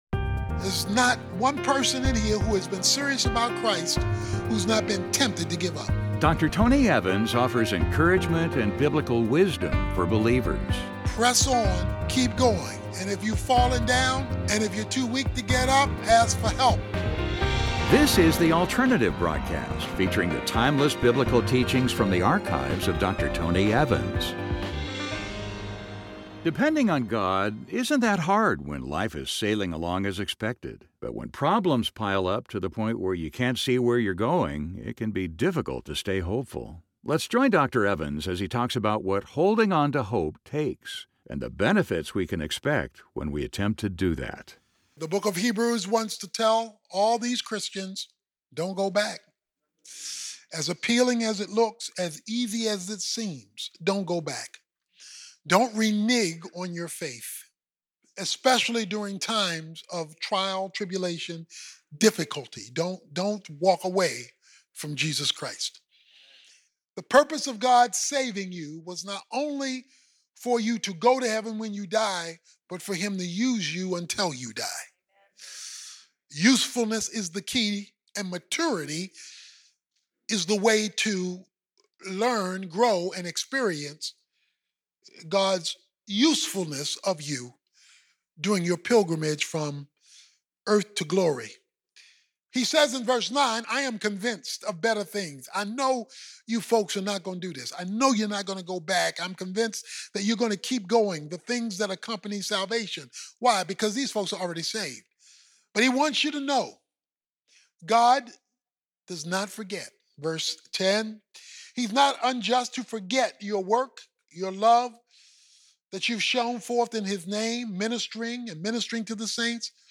But when problems begin to pile up, it can be difficult to stay hopeful. In this message, Dr. Tony Evans talks about how to hold on to hope in the midst of trying times.